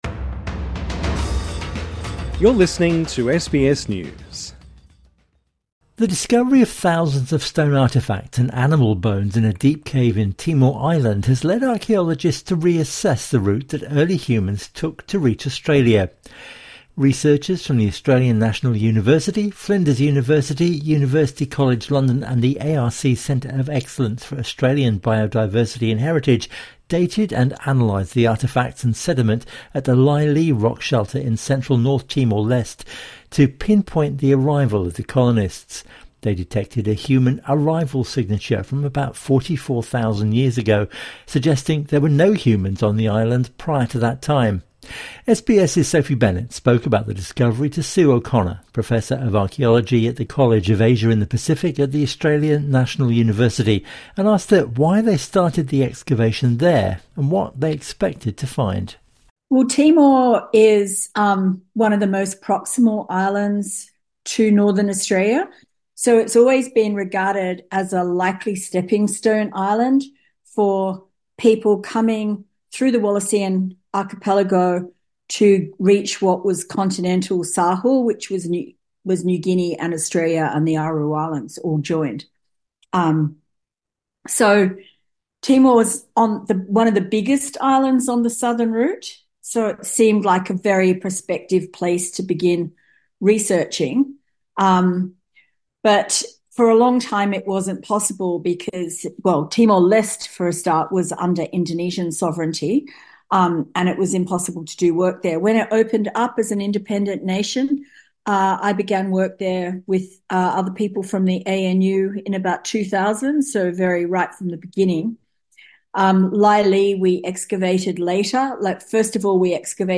INTERVIEW: How did the first humans get to Australia?